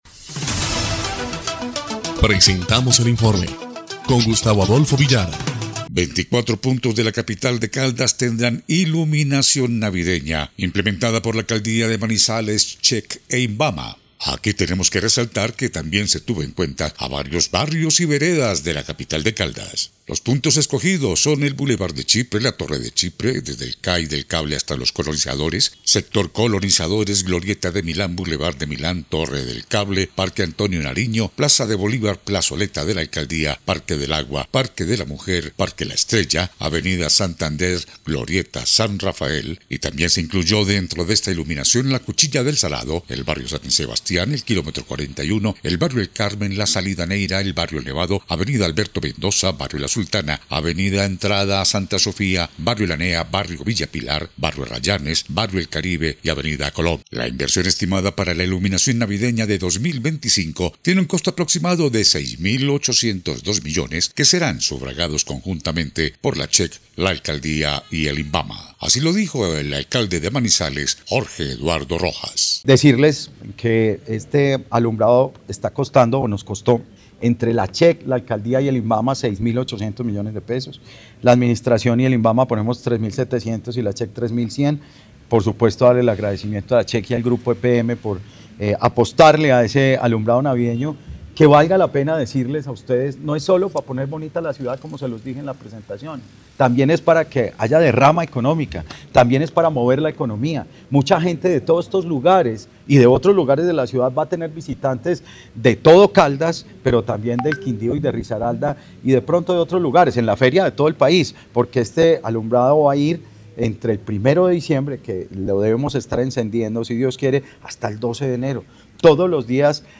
EL INFORME 1° Clip de Noticias del 20 de noviembre de 2025